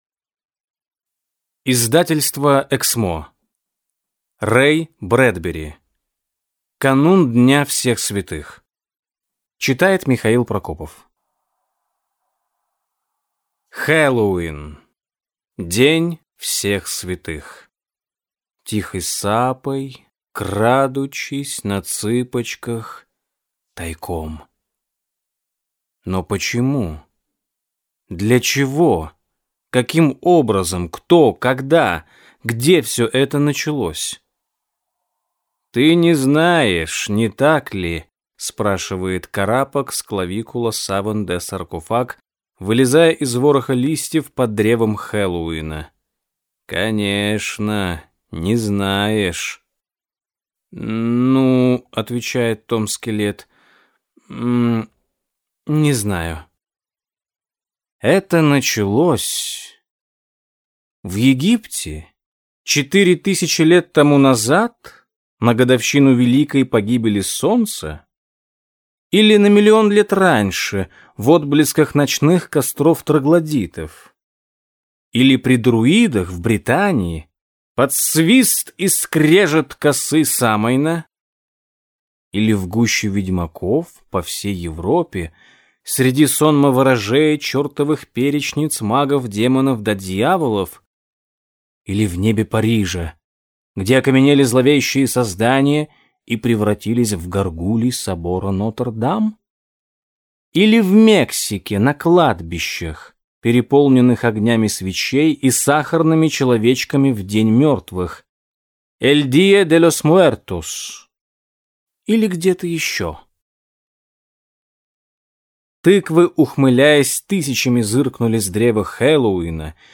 Аудиокнига Канун дня всех святых | Библиотека аудиокниг